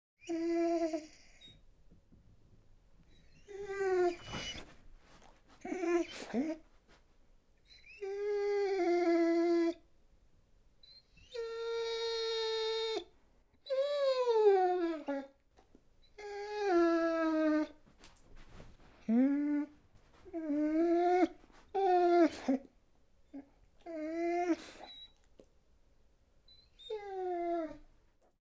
Звуки скулящей собаки
Плач собаки в звуках